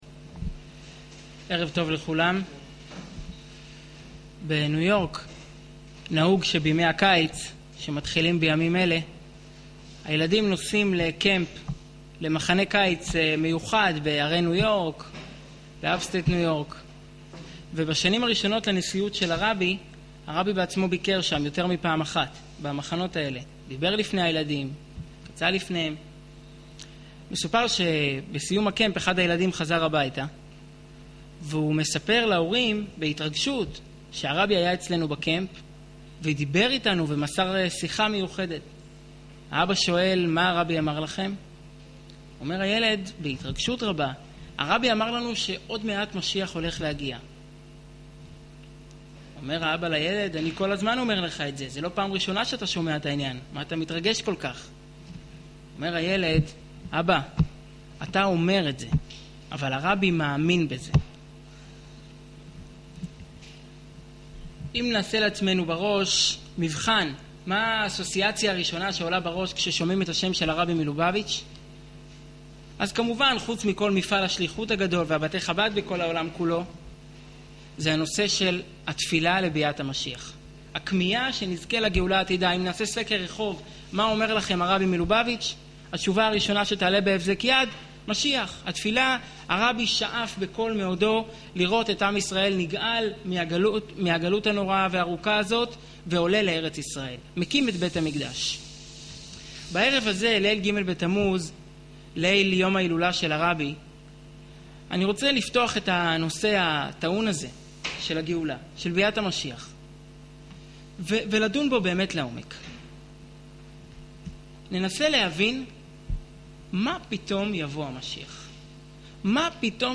למה אנחנו צריכים משיח? ● שיעור וידאו